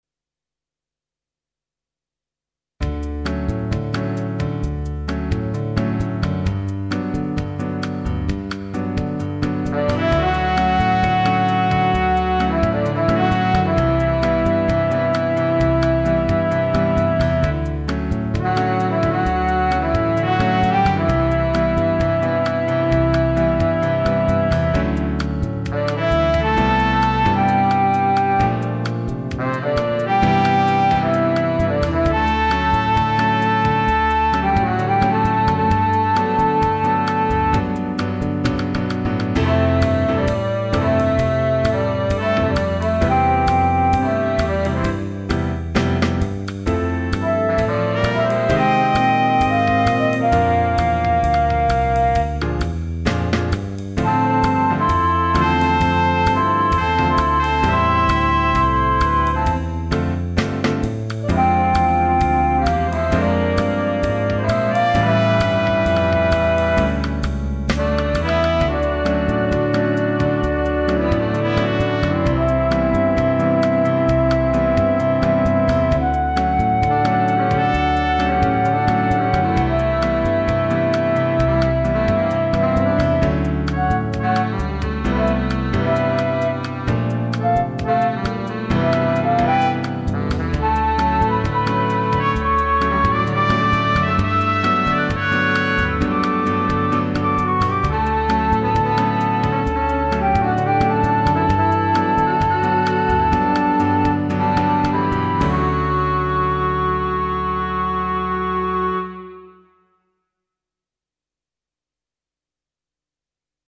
Instrumentation: C, Bb, Eb, pno, Bass, Drums / perc.
Scored for 8 part flexible ensemble